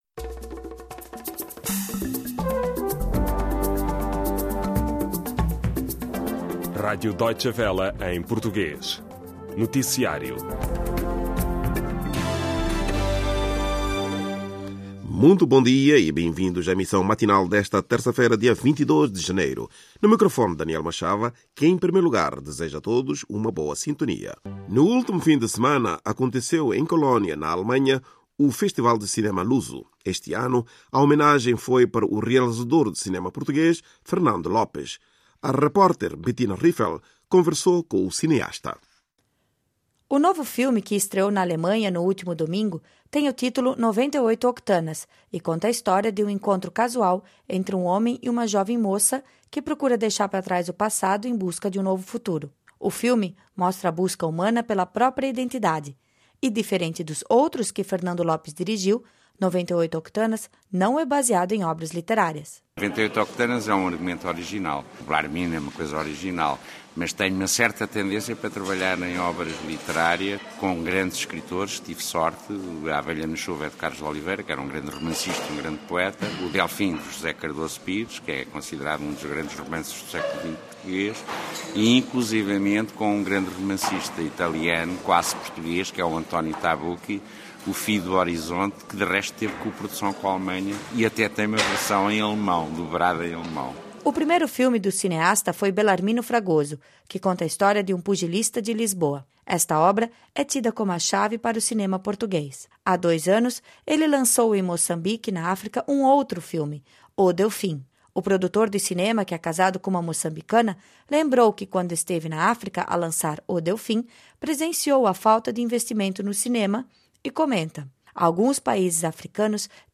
Radiobericht